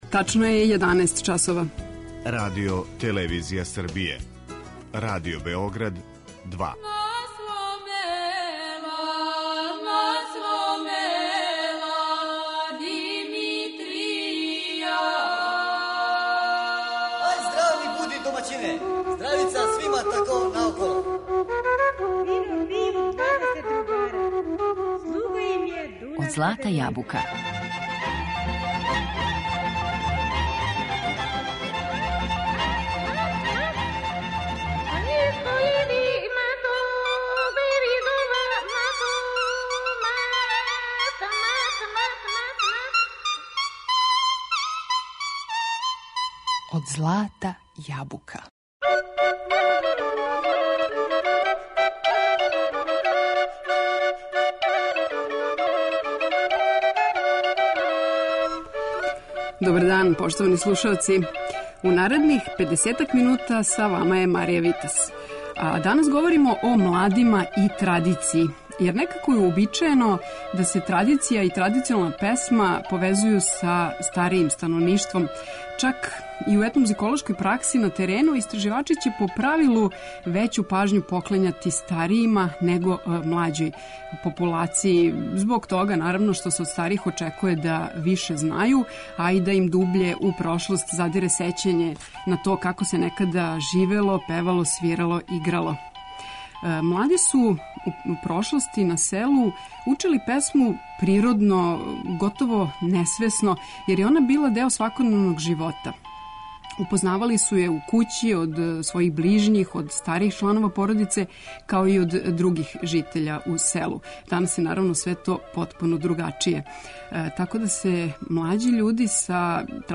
Поред песама у извођењу Етно групе "Свети Сава", слушаћемо и друге младе интерпретаторе.